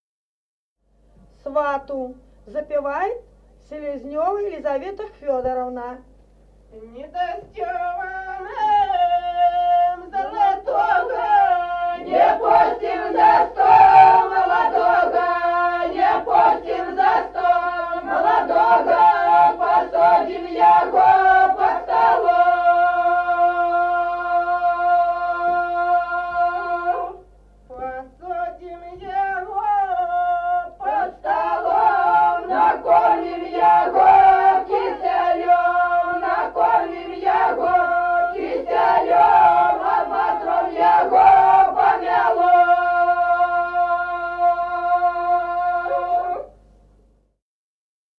Песни села Остроглядово. Не дасьтё вы нам золотого.